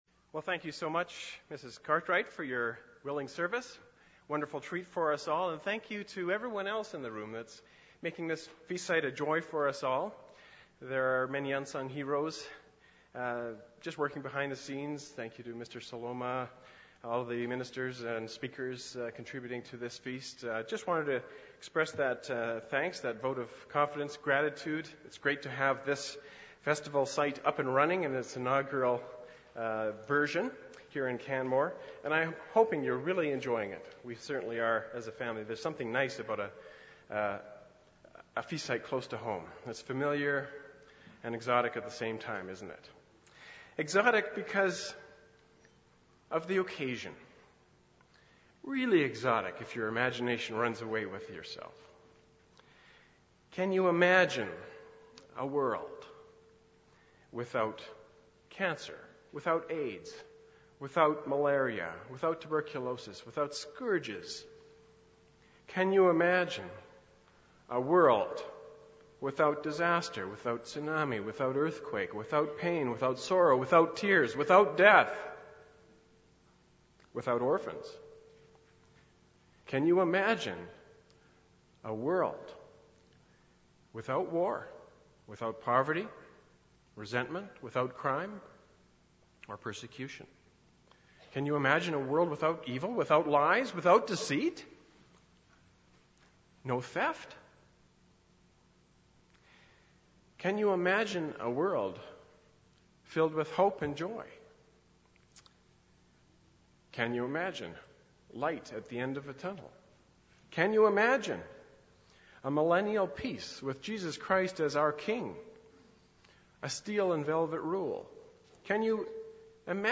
This sermon was given at the Canmore, Alberta 2011 Feast site.